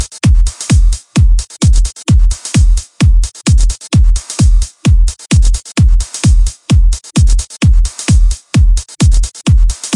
节奏 " Hard Kit Beat 2 Loop ( Wav)
标签： 节奏 2 REDRUM 帽子 军鼓 循环 理性 工具包 低音 普罗佩勒黑兹
声道立体声